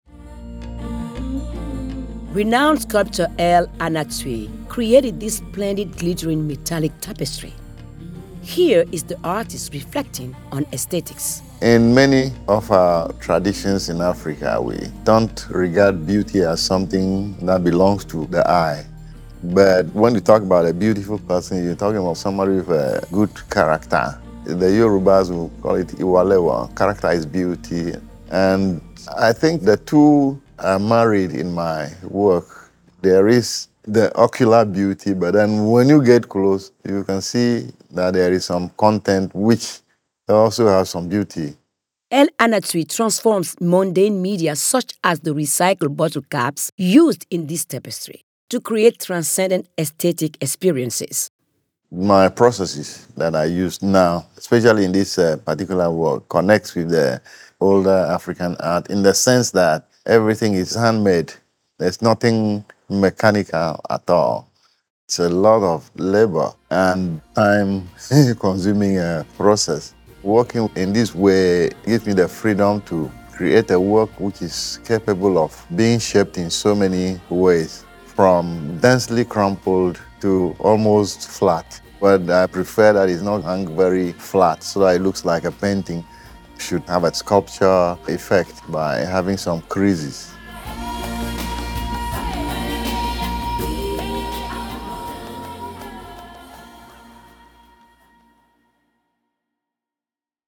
In Conversation with the Artist: Between Earth and Heaven